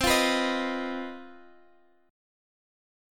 CmM7bb5 chord